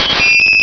Cri de Mimigal dans Pokémon Rubis et Saphir.